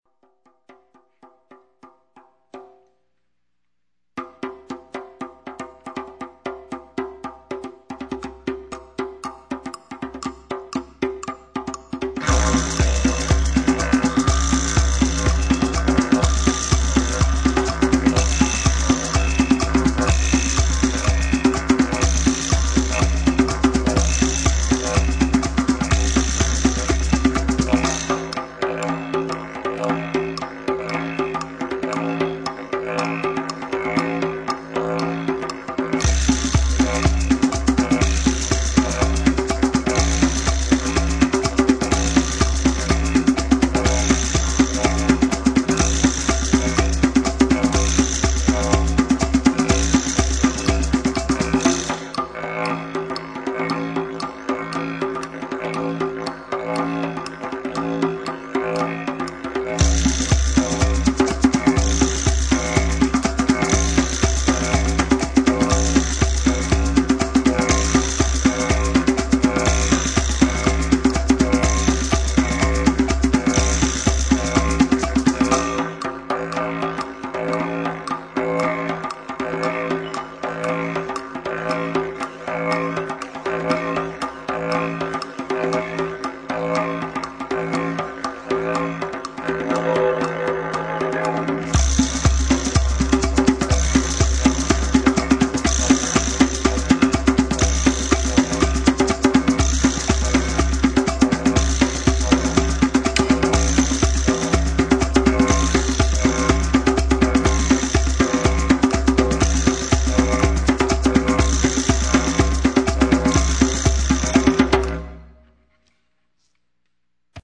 one-man percussion ensemble